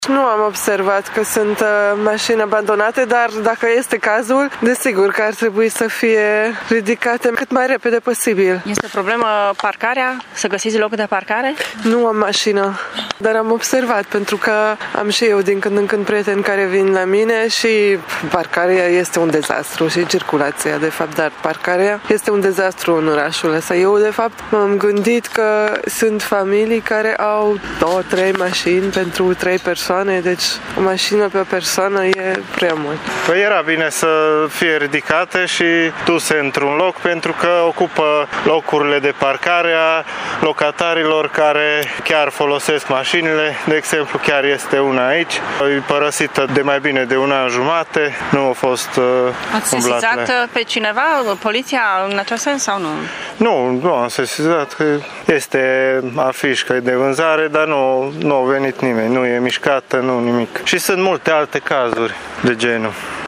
Târgumureșenii cunosc bine problema mașinilor abandonate care ocupă mult râvnitele locuri de parcare, însă unii nu se încumetă să sesizeze poliția pentru a nu-i supăra pe … vecini: